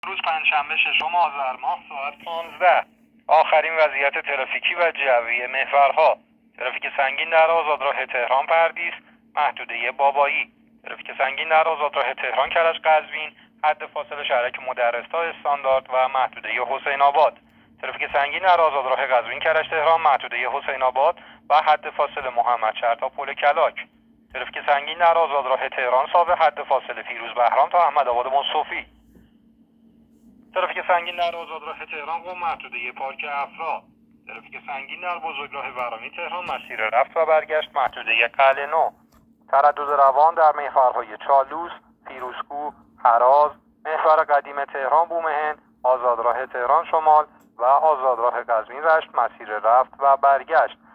گزارش رادیو اینترنتی از آخرین وضعیت ترافیکی جاده‌ها ساعت ۱۵ ششم آذر؛